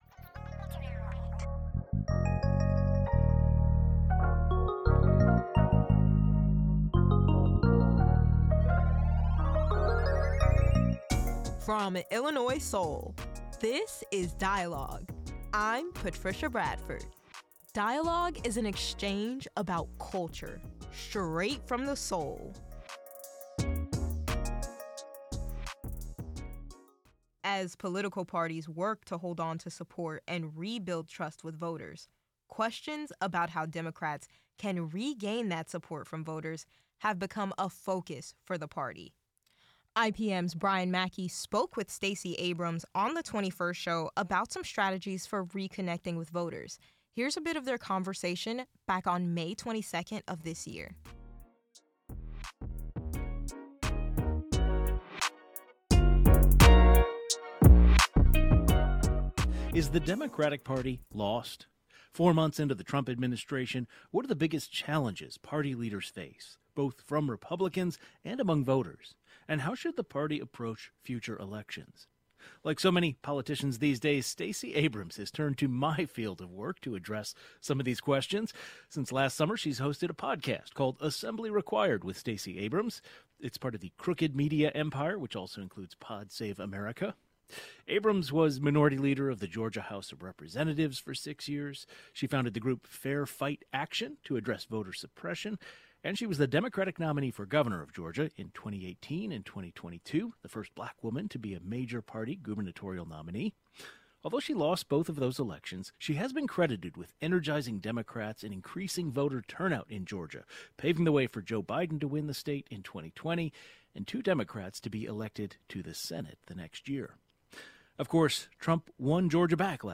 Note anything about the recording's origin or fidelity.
This segment revisits a conversation from "The 21st Show."